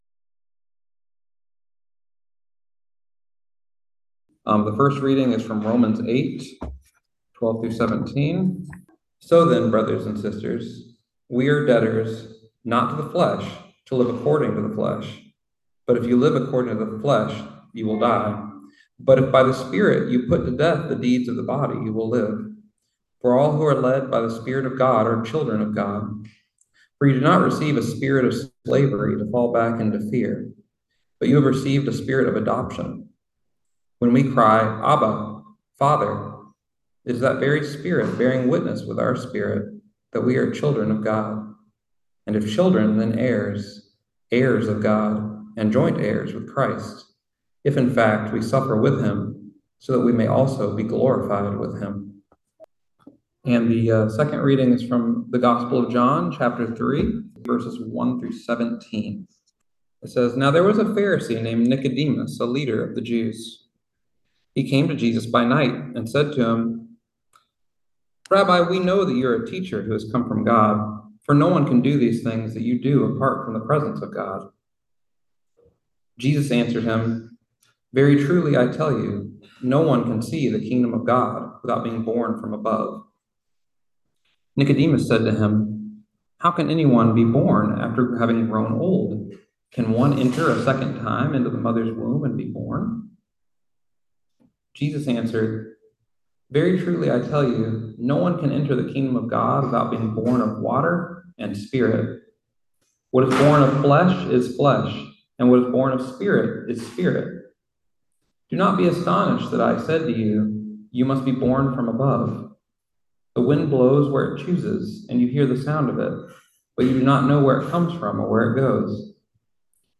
Listen to the most recent message from Sunday worship at Berkeley Friends Church, “God So Loved the World.”